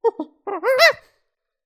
Monkey noise (imitation)
Category 🐾 Animals
ape chimp chimpanzee monkey sound effect free sound royalty free Animals